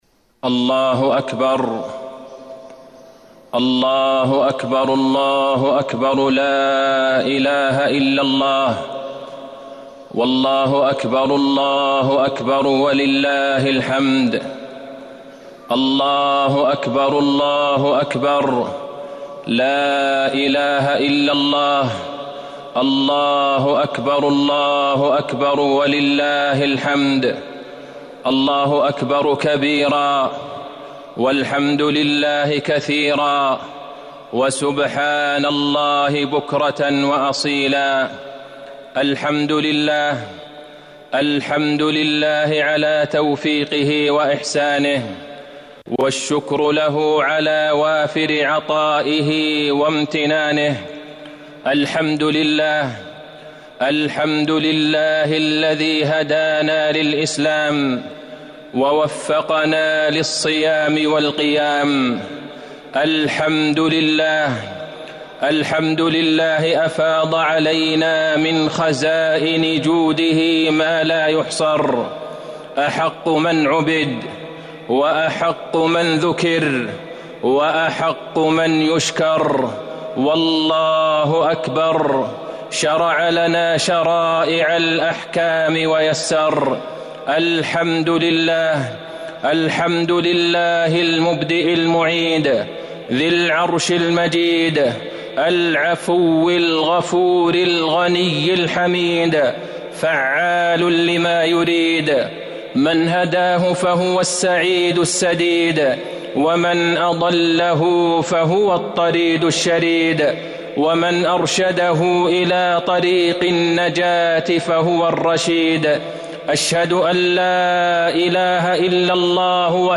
خطبة عيد الفطر- المدينة - الشيخ عبدالله البعيجان 1-10-
المكان: المسجد النبوي